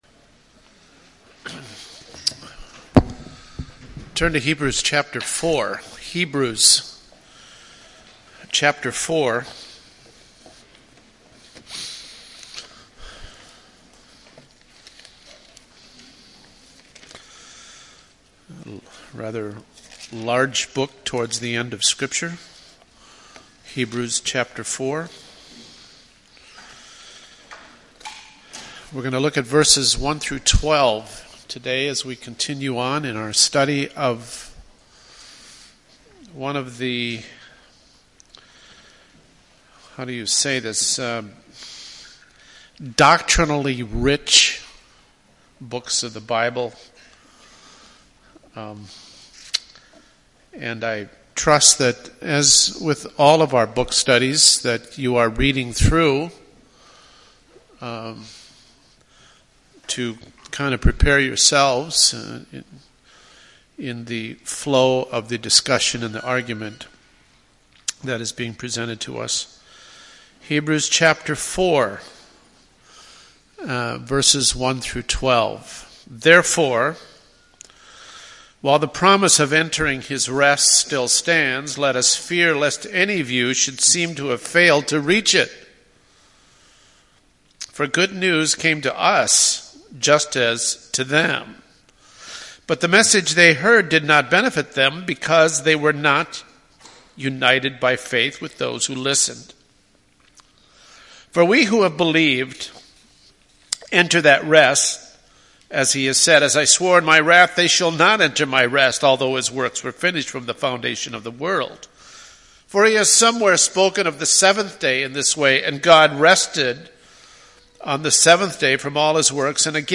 Passage: Hebrews 4:1-12 Service Type: Sunday Morning